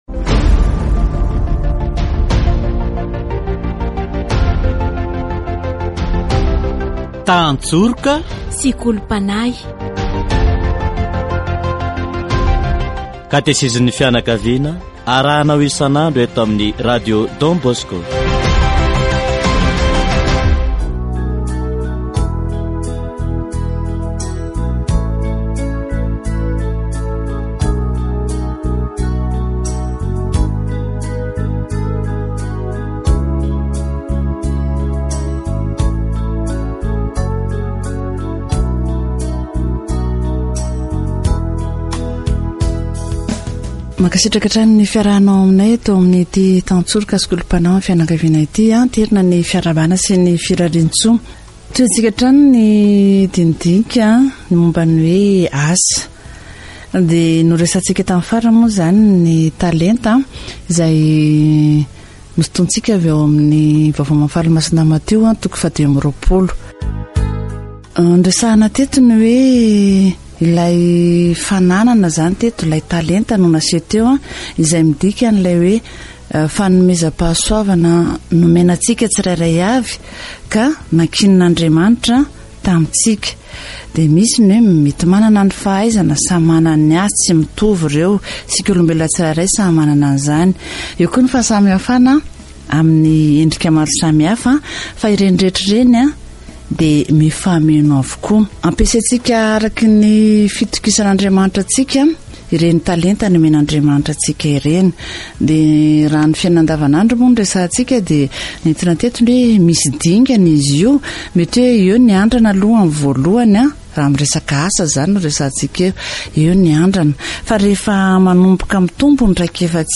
Le Seigneur nous encourage à accroître nos talents. Catéchèse sur le travail